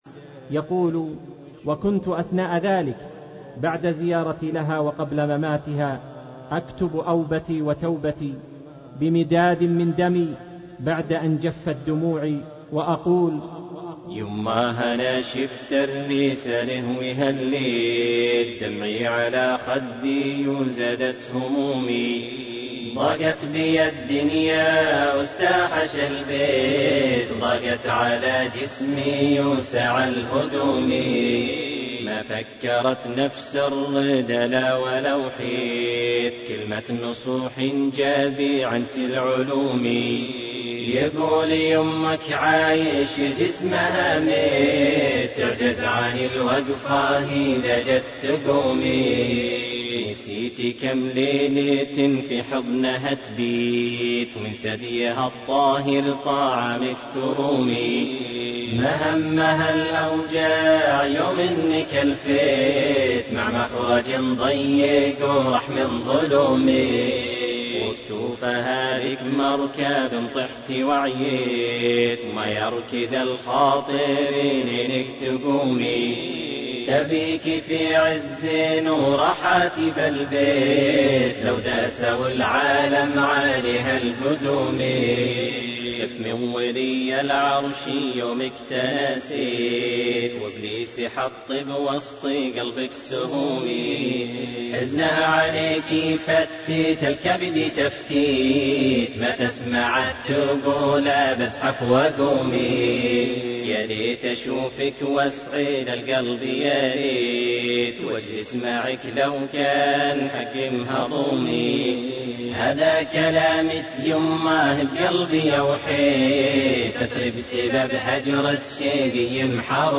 - أناشيد الإصـدار -